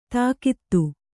♪ tākittu